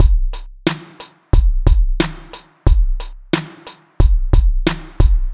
• 90 Bpm Drum Groove C Key.wav
Free drum loop - kick tuned to the C note. Loudest frequency: 1577Hz
90-bpm-drum-groove-c-key-2gM.wav